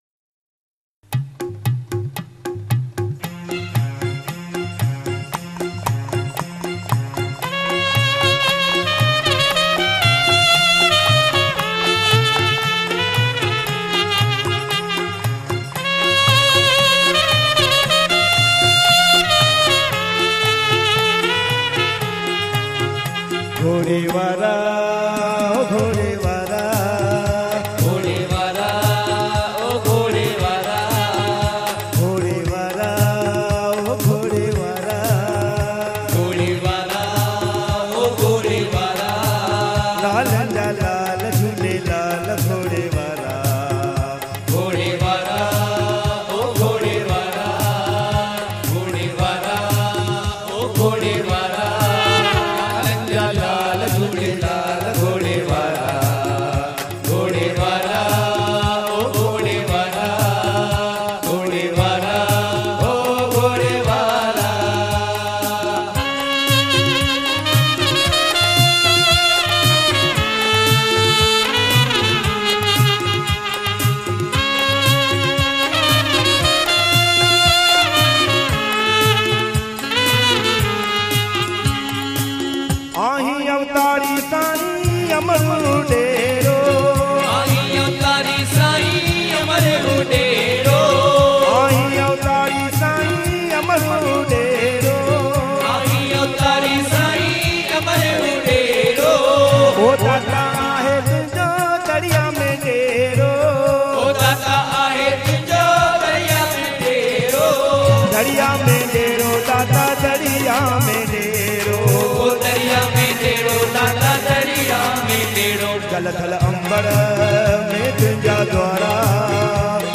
recorded at Puj Chaliha Sahib Jhulelal Mandir